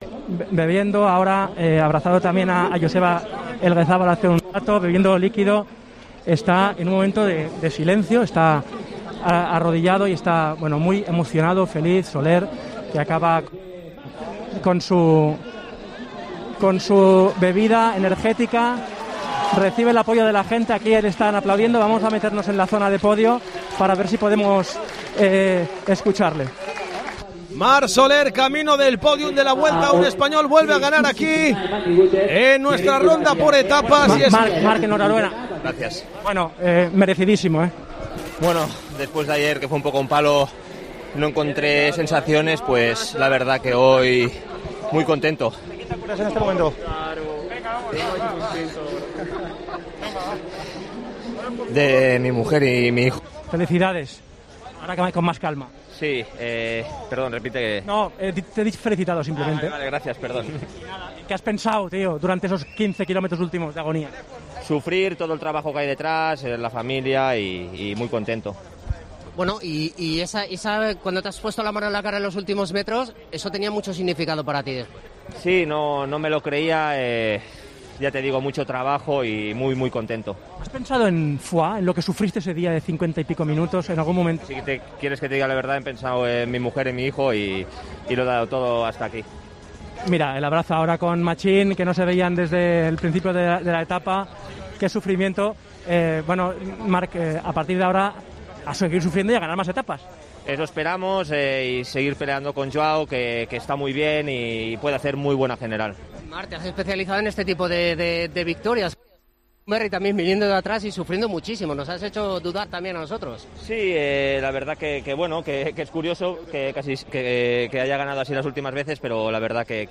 El ciclista español del UAE ha valorado el triunfo en la línea de meta de Bilbao.